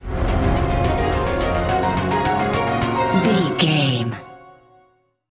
Epic / Action
Fast paced
Phrygian
frantic
intense
piano
percussion
strings
horns
brass